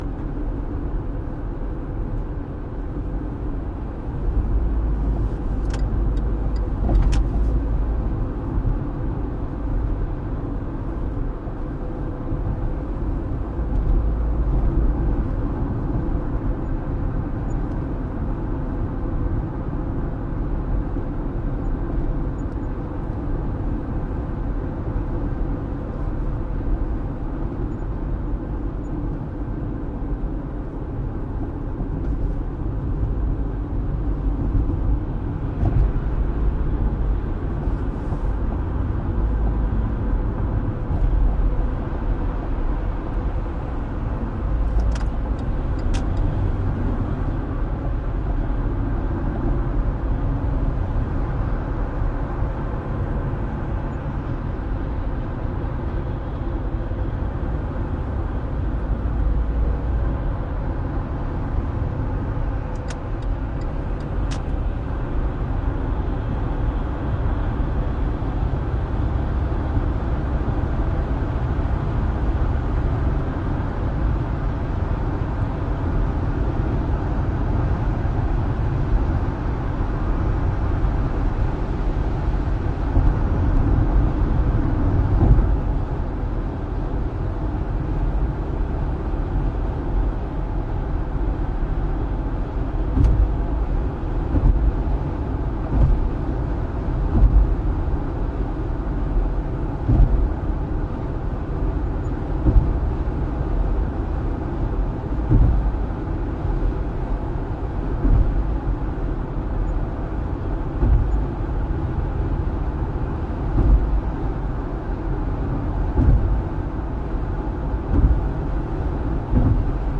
哥伦比亚 " 汽车 卡车 厢式车int 高速公路上行驶有点颠簸 窗户打开右1
描述：汽车卡车厢式车驾驶高速公路有点颠簸窗口打开1
标签： 高速公路 速度 颠簸 卡车 汽车 INT 驾驶面包车
声道立体声